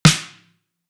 Dirty Sizzle.wav